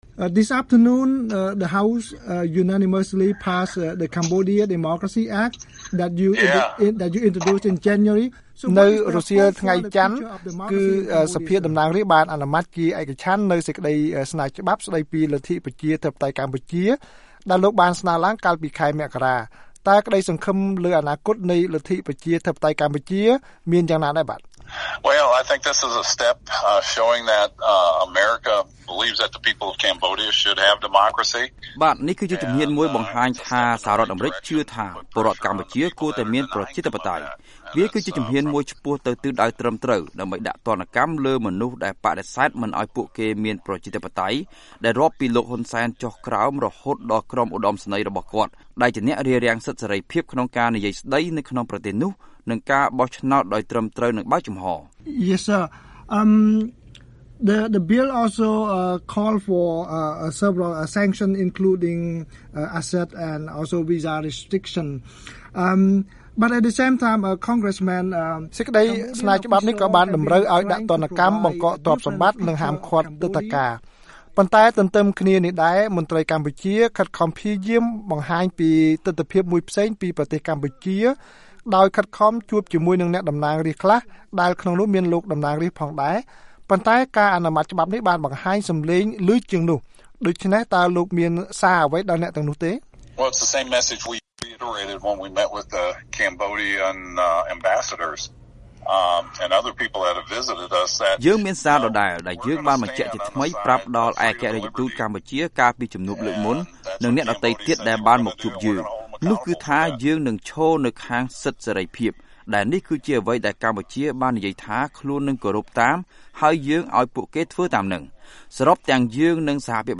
បទសម្ភាសន៍ VOA៖ លោក ថេត យូហូ ប្តេជ្ញាជំរុញឲ្យលោក ហ៊ុន សែន ទទួលខុសត្រូវលើពាក្យសន្យា